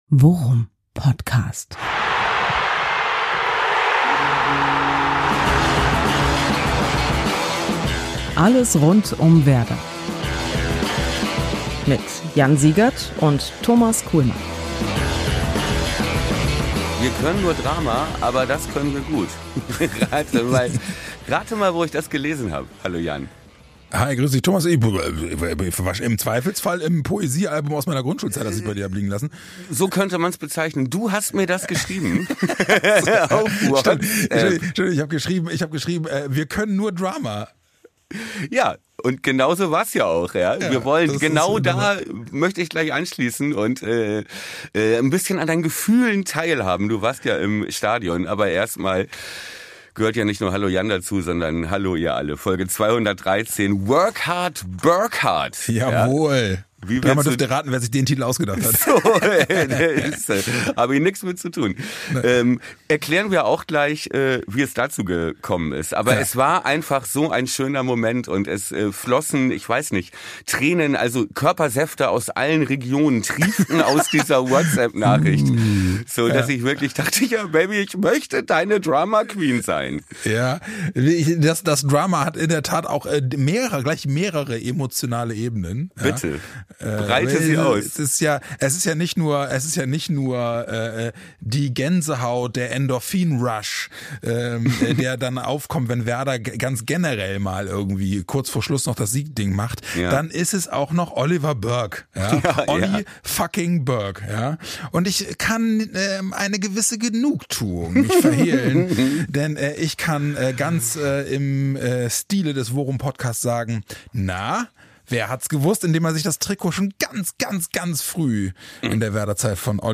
Macht Euch gefasst auf viel Gejubel und ein wenig Gemaule.